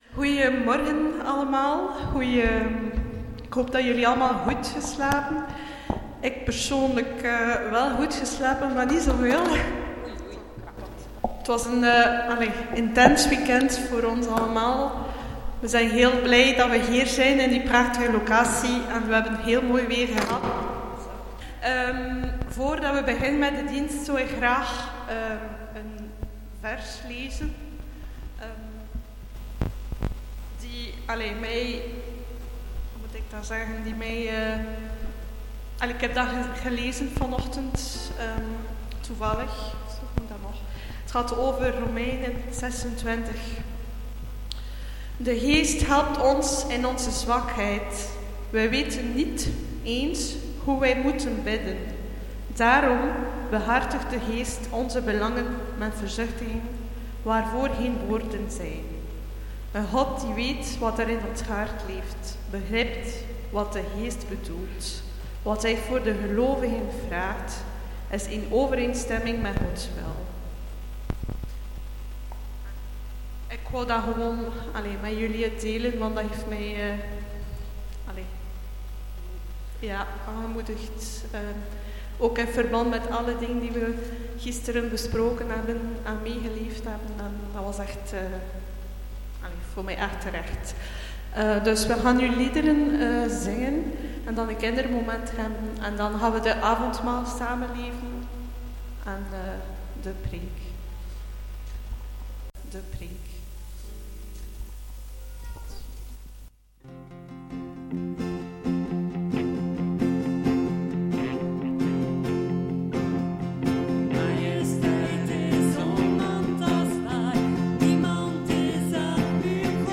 Passage: Spreuken 16 : 4 Dienstsoort: Gemeenteweekend « De Toren van Babel